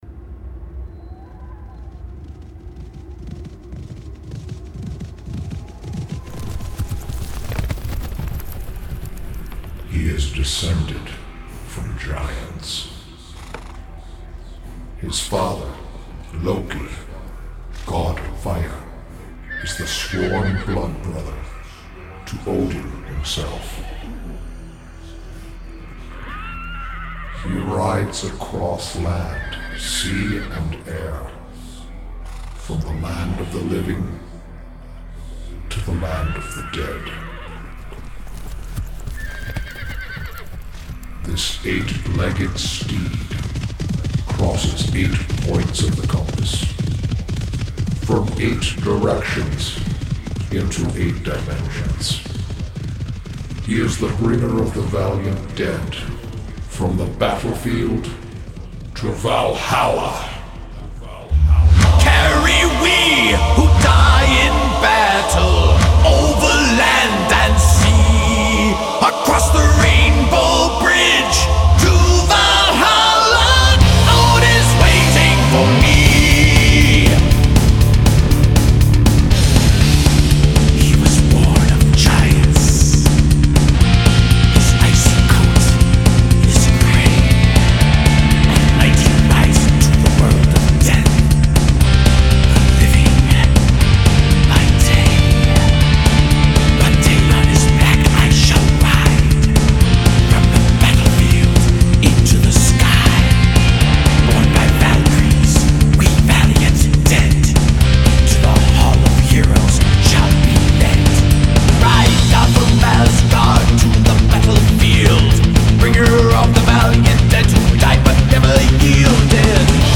BPM240--1
Audio QualityPerfect (Low Quality)